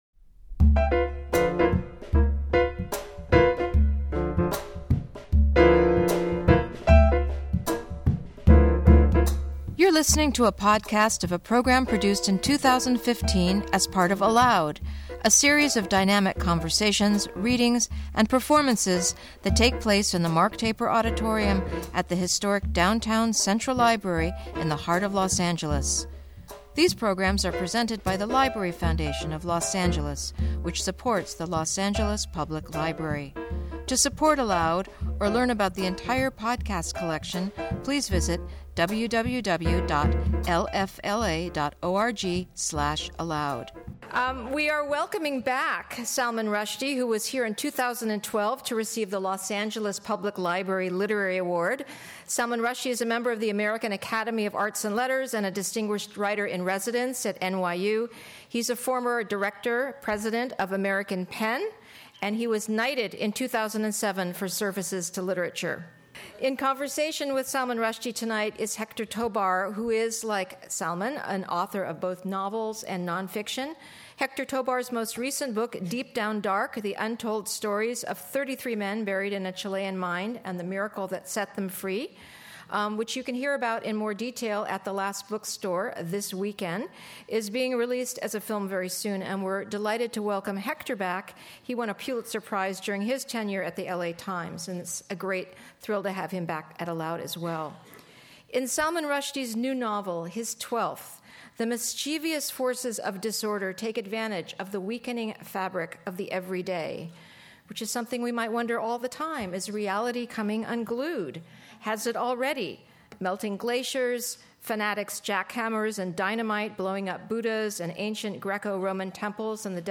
email Salman Rushdie in Conversation With Héctor Tobar Thursday, September 10, 2015 01:10:16 ALOUD Listen: play pause stop / rushdie.mp3 Listen Download this episode Episode Summary Returning to ALOUD after receiving the 2012 Los Angeles Public Library Literary Award for his distinguished commitment to libraries and literature, Rushdie shares his newest work of fiction.
Discussing this work with Héctor Tobar, one of L.A.’s most respected voices, Rushdie takes the stage for a magical evening of storytelling.